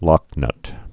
(lŏknŭt)